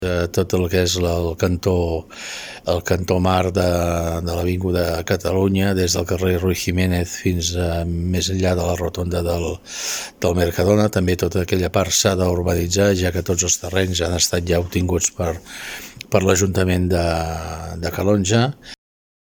Jordi Soler explica que és una zona on hi ha terrenys cedits a l’Ajuntament que han de ser urbanitzats perquè siguin completament funcionals.